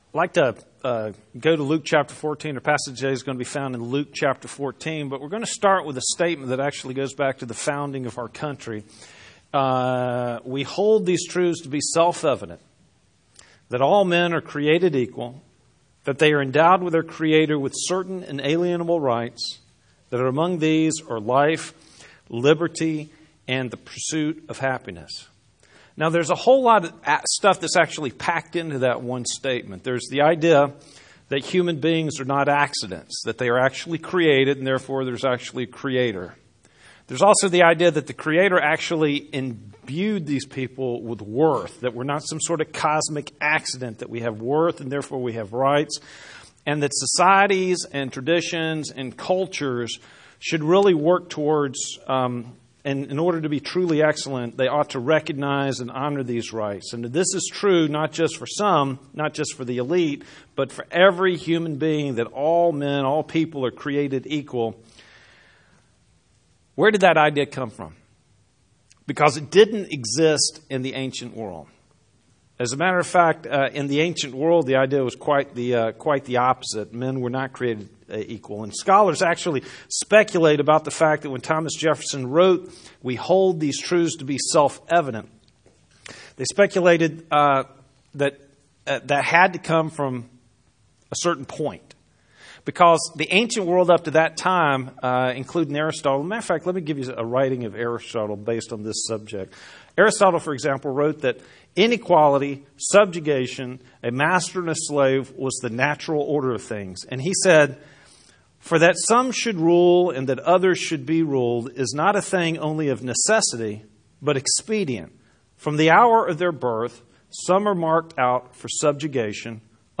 Sermon Video & Audio